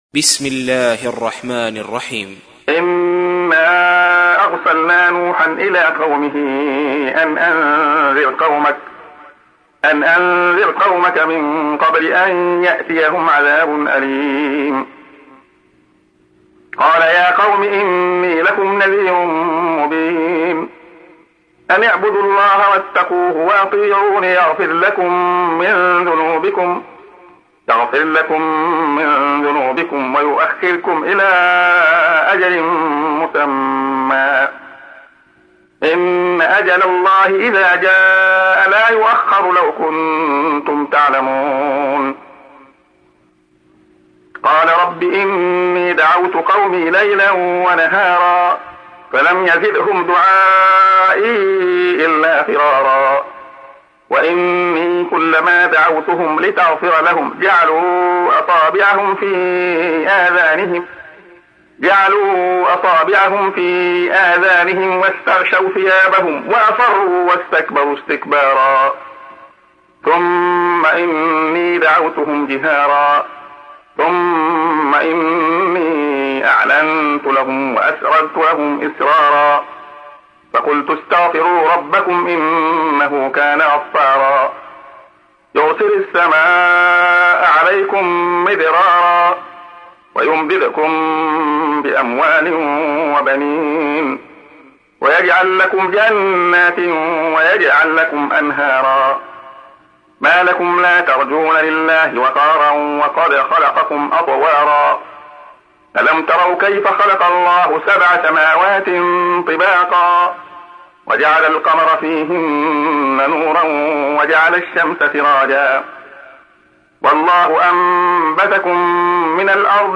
تحميل : 71. سورة نوح / القارئ عبد الله خياط / القرآن الكريم / موقع يا حسين